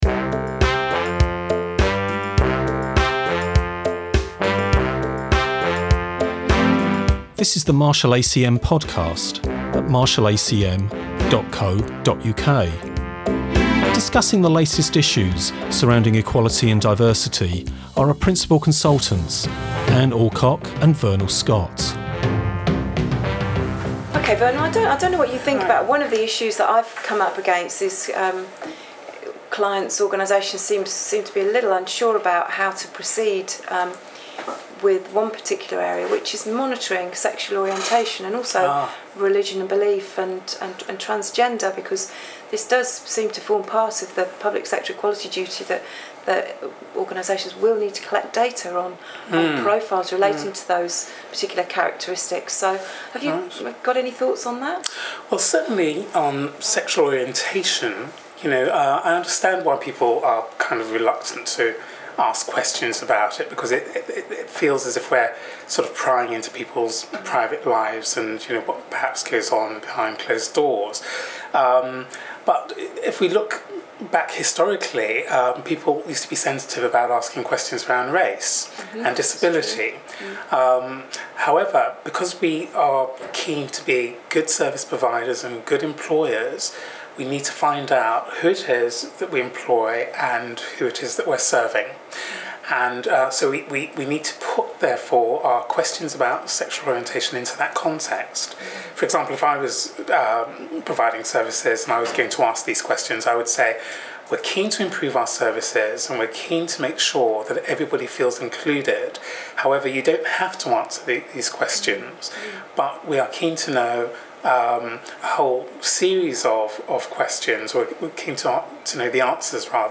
discussing the latest issues surrounding equality and diversity.